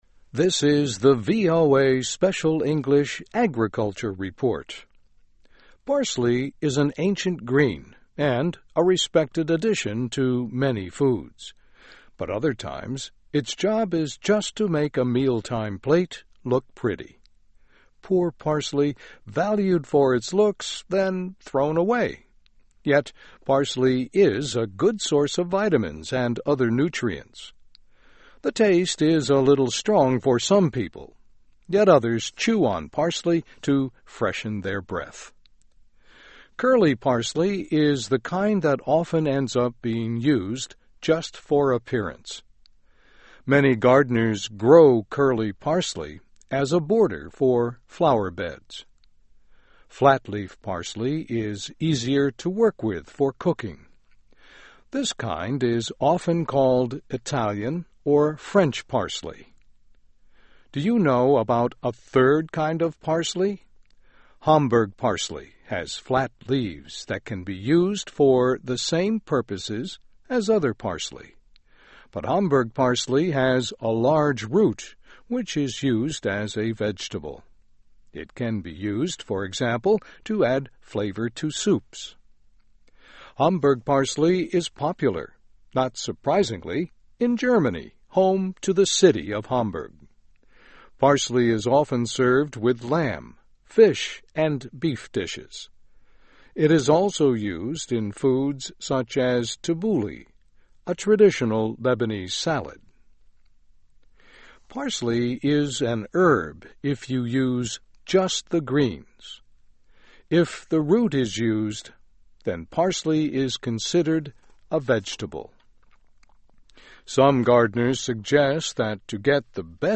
Topic: Advice for growing the plants. Transcript of radio broadcast.